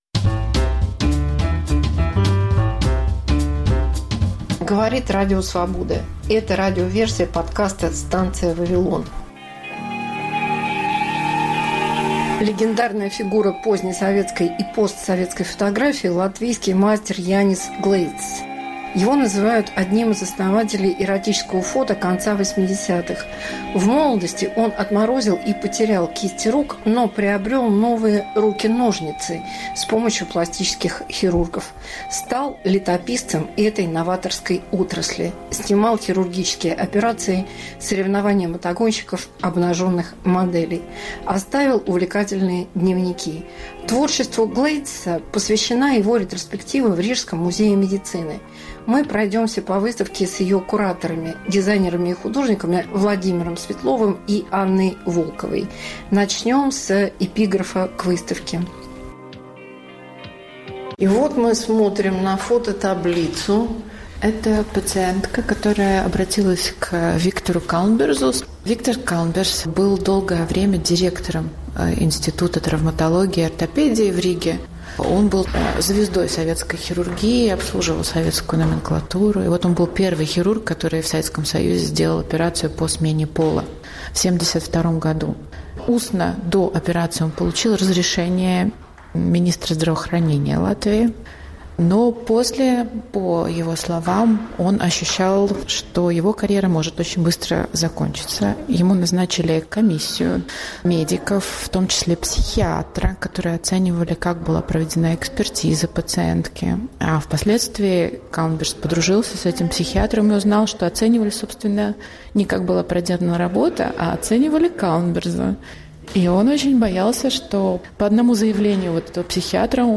Мегаполис Москва как Радио Вавилон: современный звук, неожиданные сюжеты, разные голоса